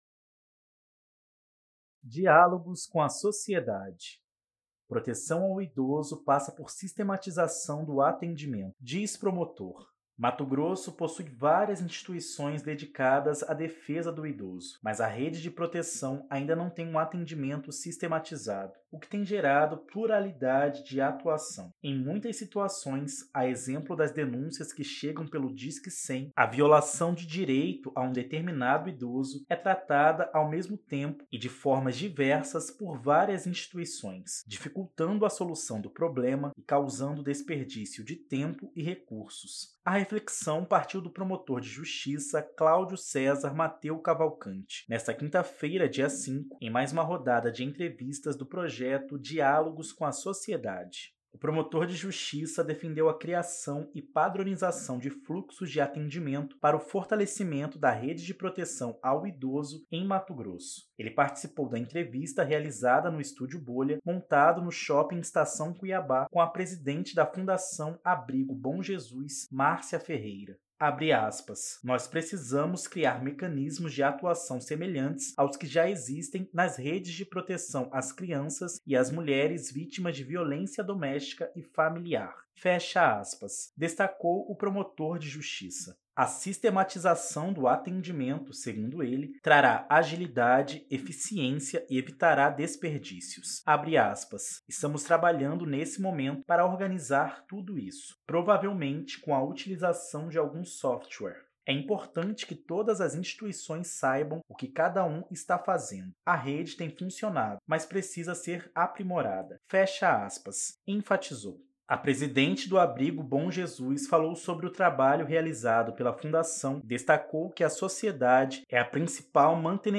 A reflexão partiu promotor de Justiça Claudio Cesar Mateo Cavalcante, nesta quinta-feira (05), em mais uma rodada de entrevistas do projeto “Diálogos com a Sociedade”.